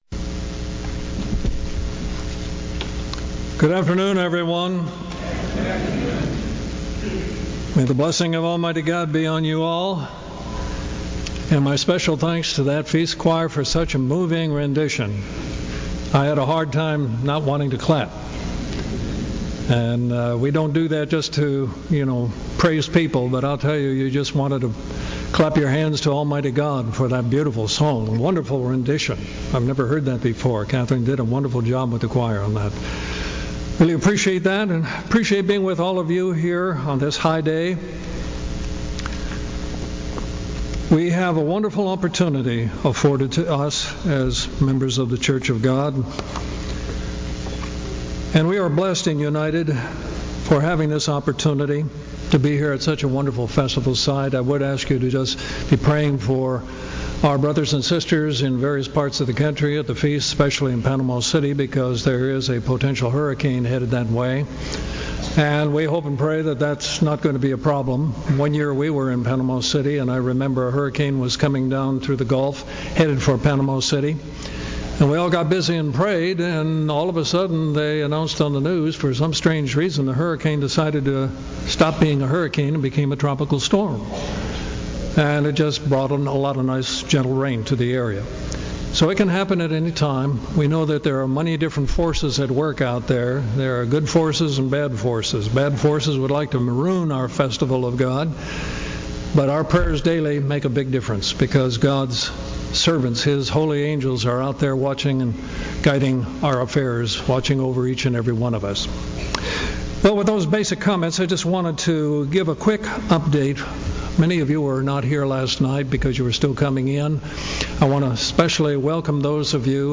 Sermons
2017 Feast of Tabernacles - Jekyll Island, Georgia